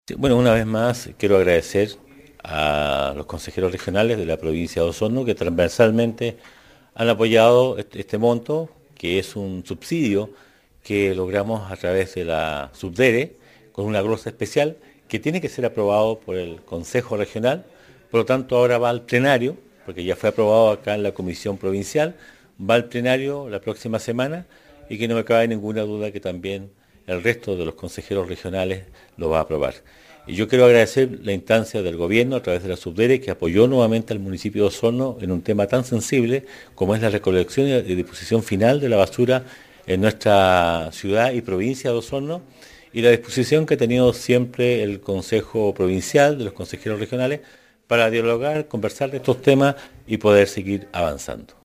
El alcalde señaló que donde se ubica el vertedero actual en Curaco hay una superficie total aproximada de 31.5 hectáreas (área circunscrita por el cerco perimetral) y la superficie utilizada para la disposición de RSD actual corresponde a cerca de 27,3 hectáreas, por lo que se requieren de esos recursos para continuar con el trabajo que realizan.
04-octubre-23-Emeterio-Carrillo-Residuos.mp3